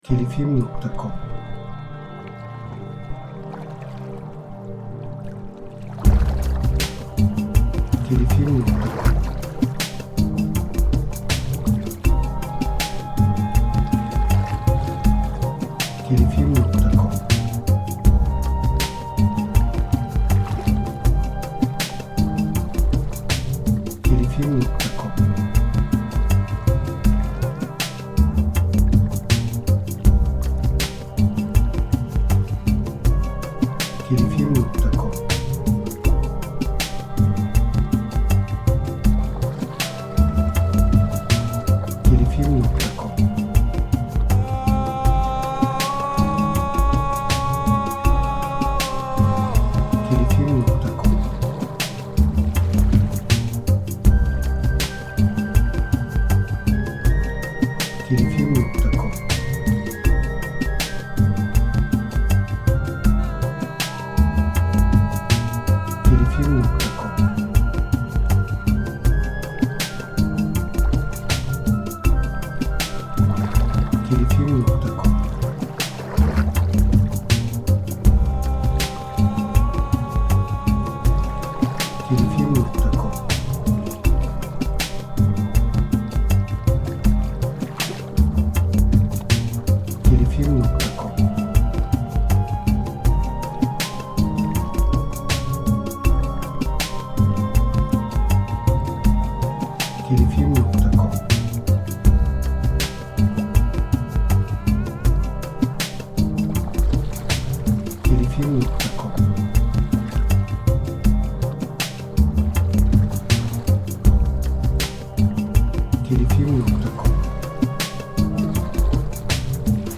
Eser Türü : Müzikal Tema Eser Tipi : Enstrümental